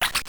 puddle.ogg